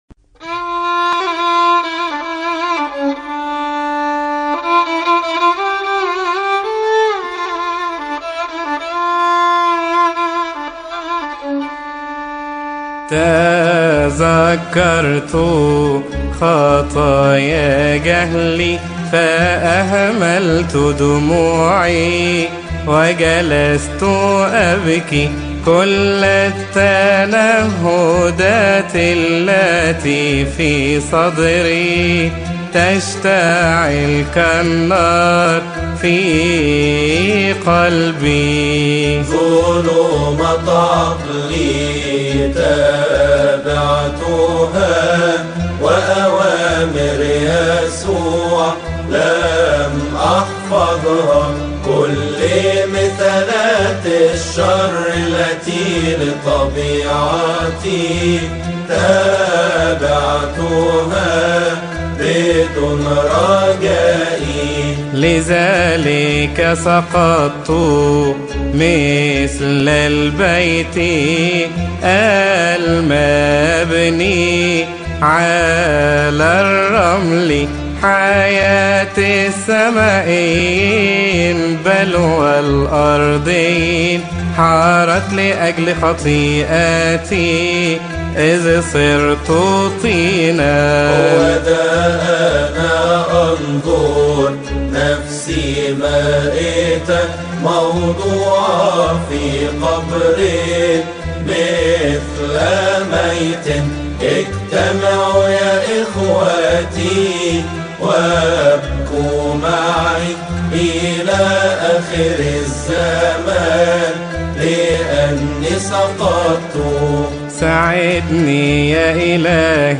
إبصالية أدام على الهوس الثالث-الصوم الكبير